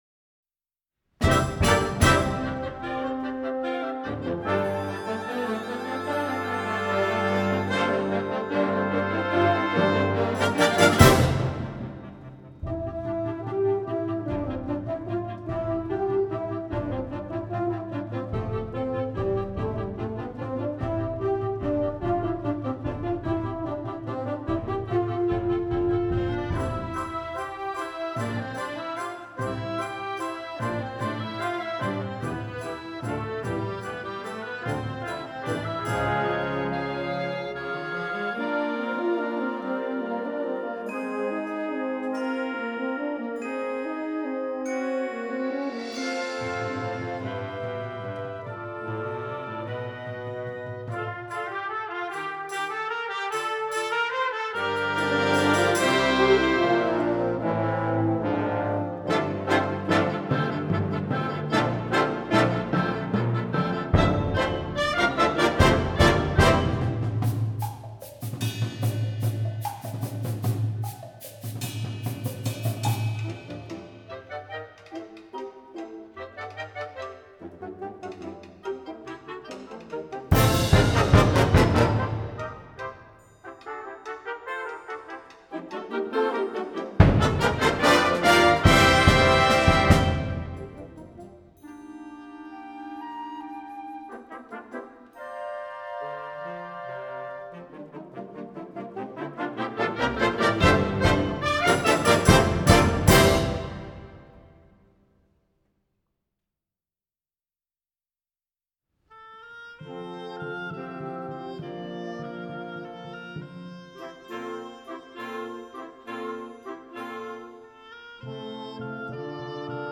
Concert Band Level